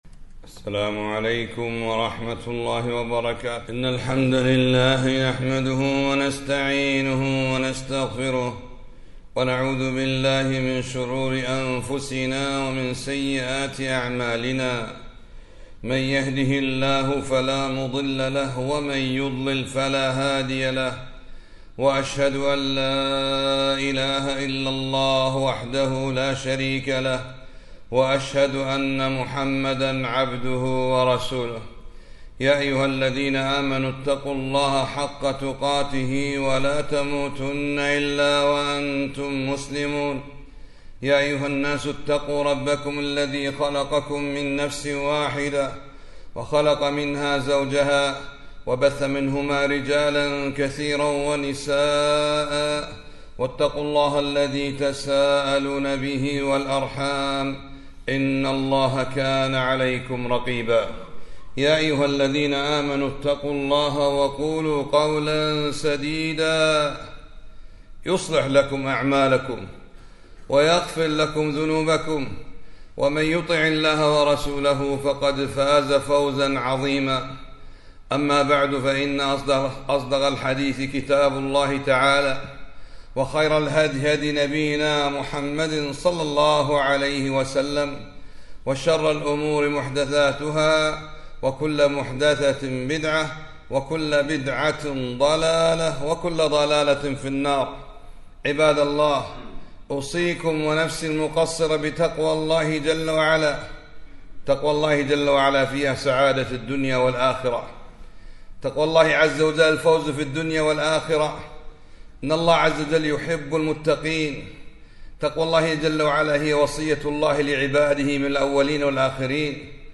خطبة - أليس الله بأحكم الحاكمين